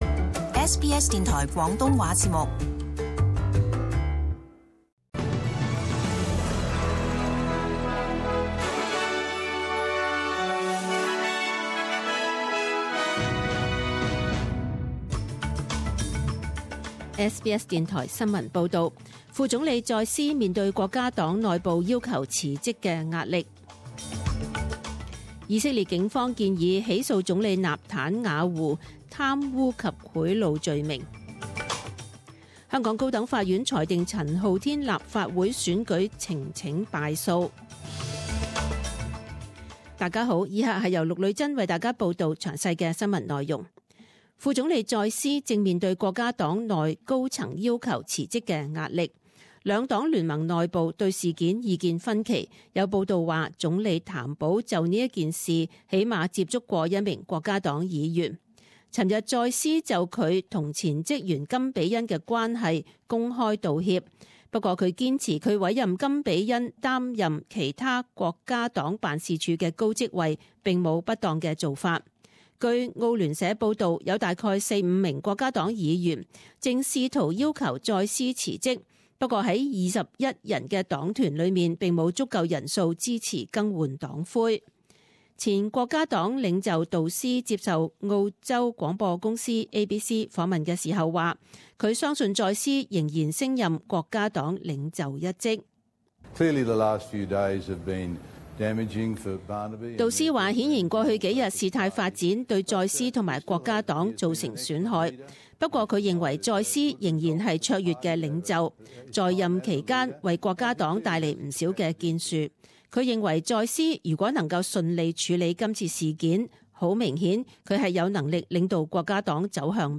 十點鐘新聞報導 （二月十四日）